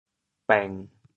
潮州では、「bêng1着」と言うか。